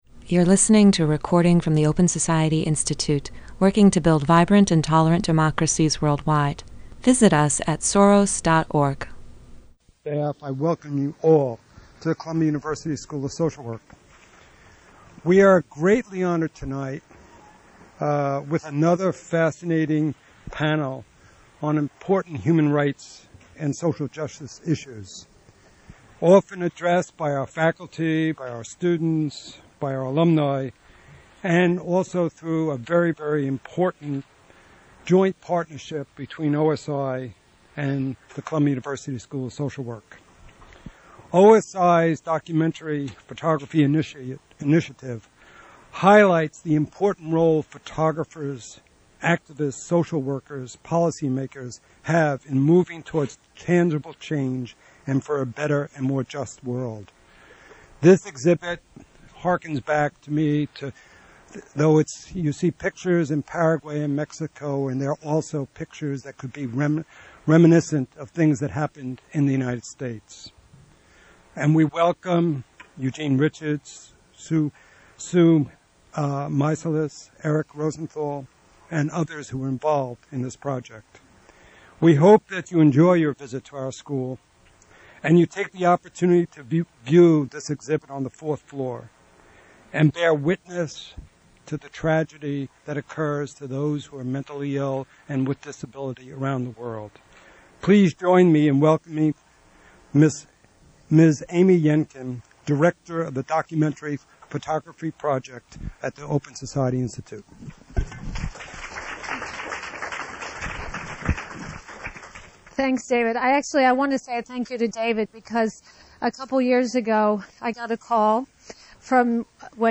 OSI’s Documentary Photography Project presented a discussion about the use of photography in advocating for the rights of those with mental and developmental disabilities.
The panel is part of the ongoing “Photography as Advocacy?” series, which explores how photography can be used to shape public policy and perception and to effect social change. Moderated by the curator of OSI's Moving Walls exhibit, photographer Susan Meiselas, the panel featured: Eugene Richards, photographer